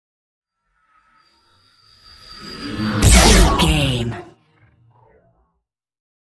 Sci fi shot whoosh to hit
Sound Effects
futuristic
intense
whoosh